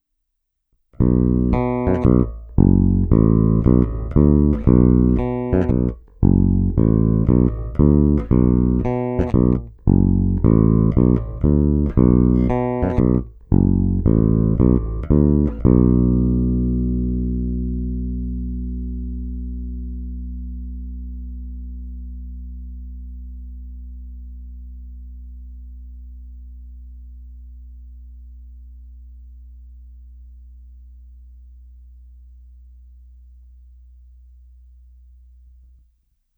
Zvuk má výrazný moderní charakter, nejspíše i díky dvoucívkovým snímačů mi zvuk přišel nepatrně zastřenější, ale není problém lehce přitlačit na korekcích výšek, dodat tam tak průzračnost a vzdušnost.
Není-li uvedeno jinak, následující nahrávky jsou provedeny rovnou do zvukové karty. Korekce basů a výšek jsem trochu přidal, středy nechal na středu.
Snímač u kobylky